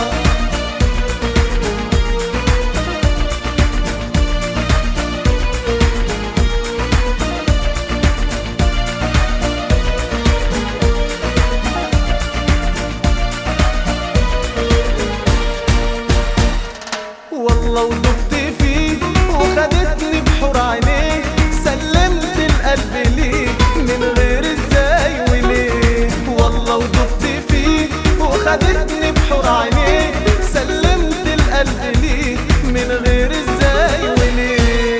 Жанр: Танцевальные / Поп / Рок